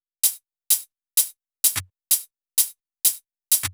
VTDS2 Song Kit 128 BPM Rap 1 Out Of 2
VTDS2 Song Kit 06 Rap 1 Out Of 2 Closed.wav